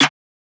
{Snr} Ends2(1).wav